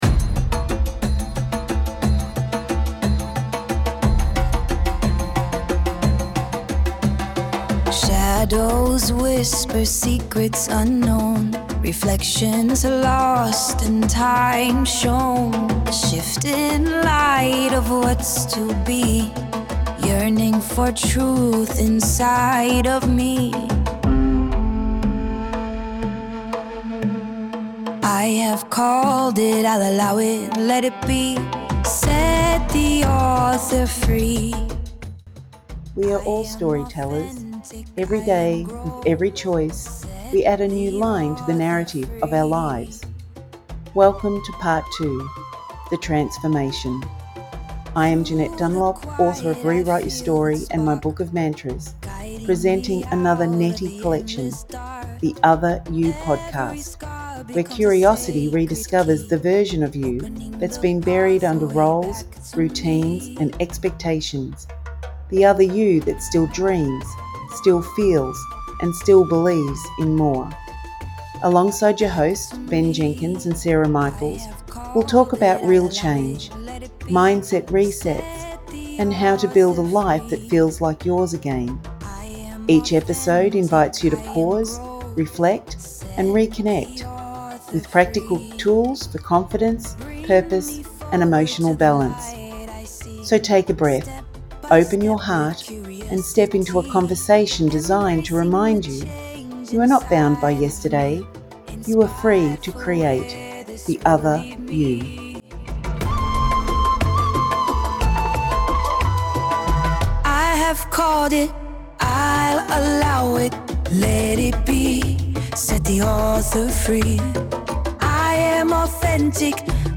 Expect mini exercises, a quick role play and language you can borrow right now.